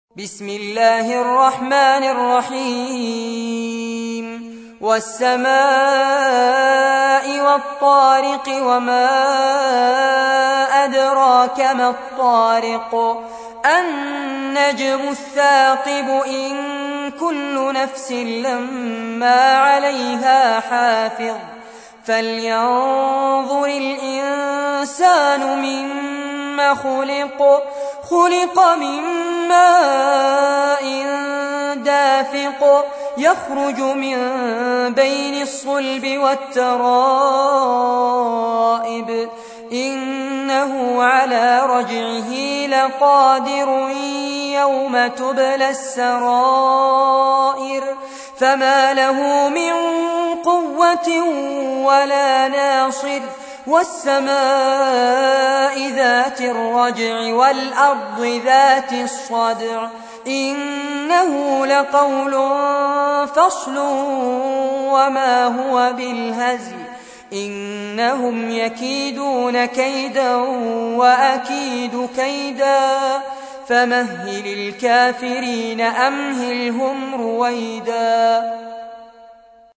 تحميل : 86. سورة الطارق / القارئ فارس عباد / القرآن الكريم / موقع يا حسين